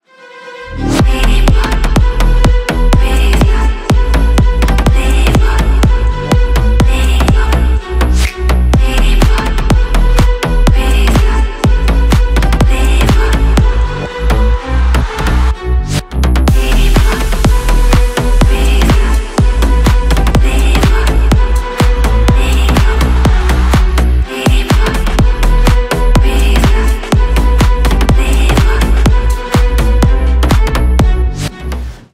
• Качество: 320 kbps, Stereo
Ремикс
ритмичные